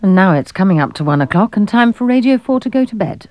Shut Down Wavs
Wav World is the home of comedy desktop sounds.